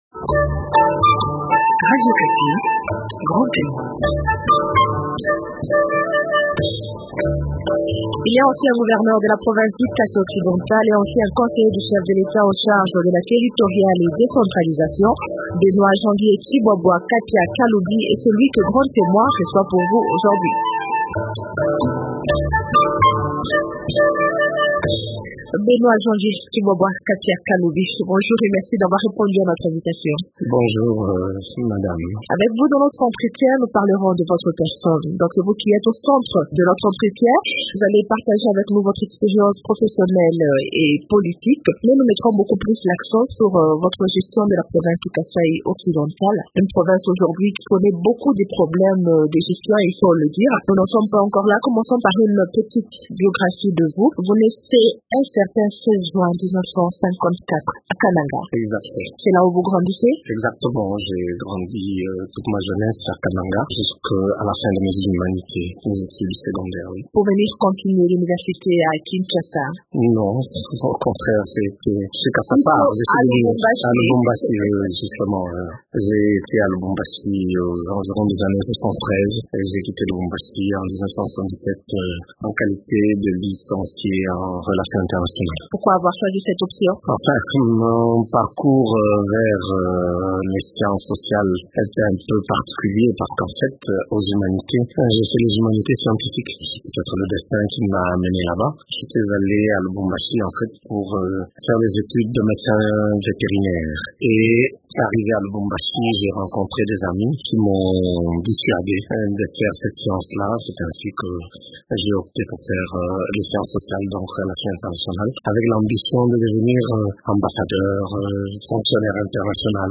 Benoît Janvier Tshibwabwa Kapya Kalubi, ancien gouverneur du Kasaï occidental Benoît Janvier Tshibwabwa Kapya Kalubi est ancien gouverneur de la province du Kasaï occidental et ancien conseillé du chef de l Etat en charge de la territoriale et décentralisation (2002-2005).